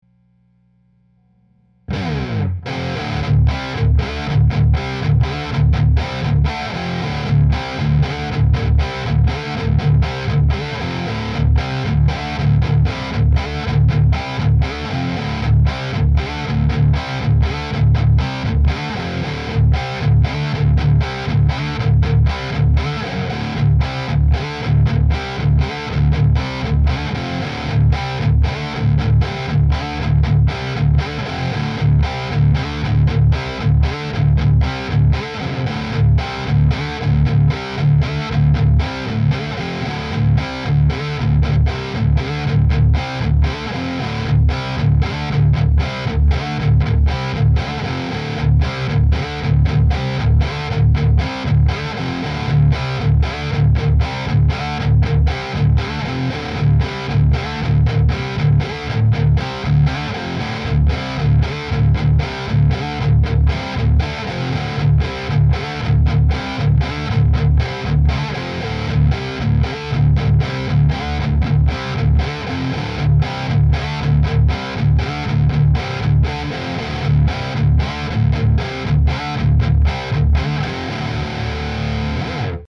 Guitar - Triple mic (Neve Pre).mp3